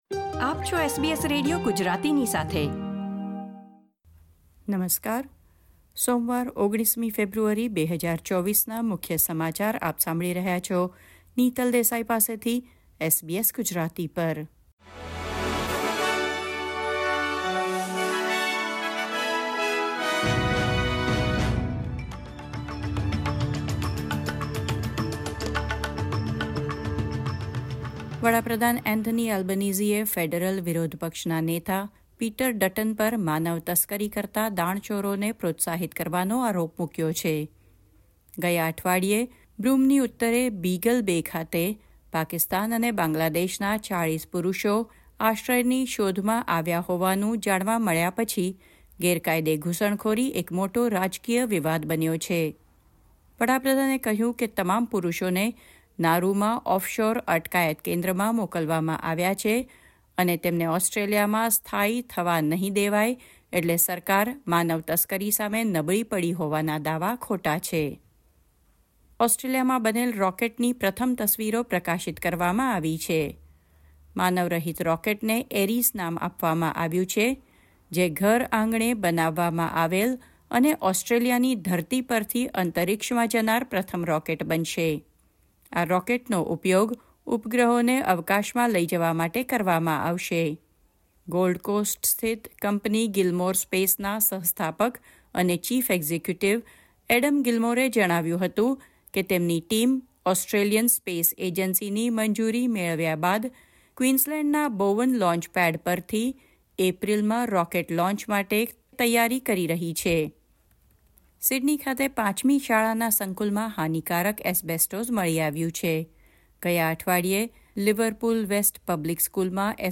SBS Gujarati News Bulletin 19 February 2024